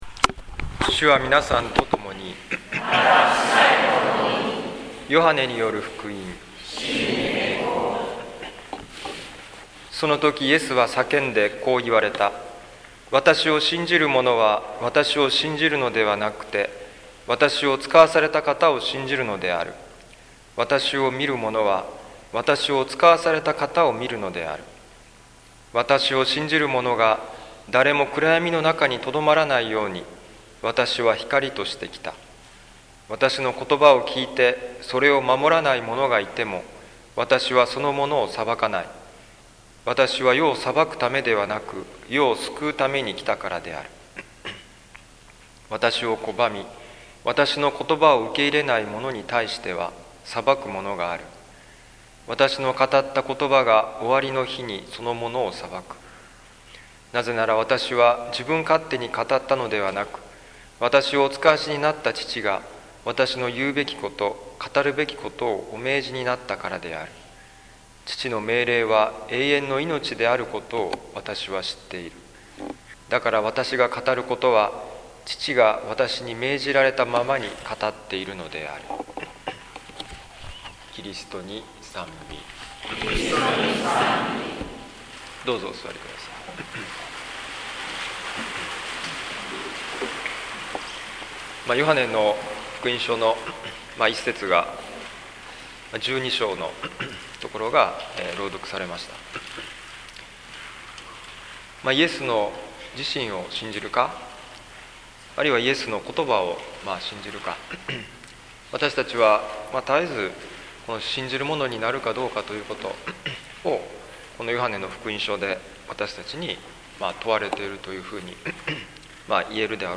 説教ライブ
癒しのミサ 東京にて